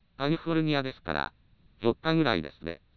以下に本システムで作成された合成音声を状態継続長の符号化手法別にあげる